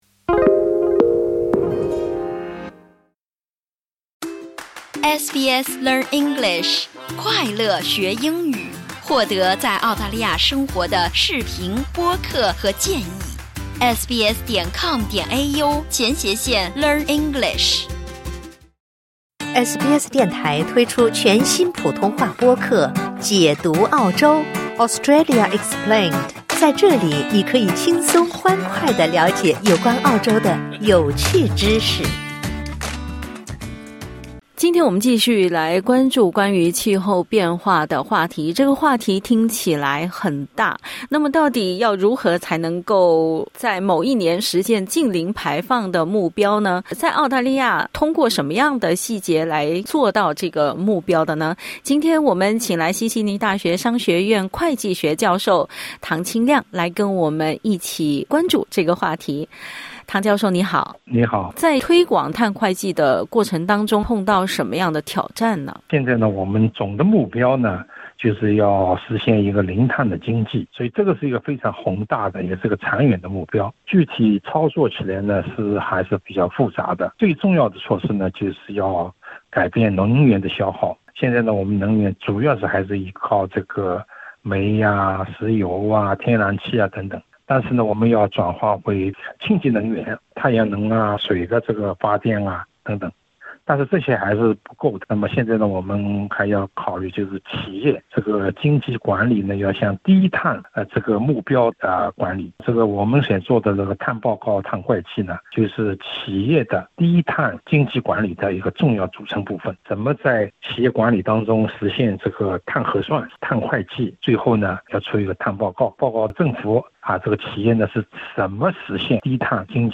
（点击音频收听详细采访） 专家警告，澳大利亚需要迅速降低碳排放量，不能等到2050年才行动。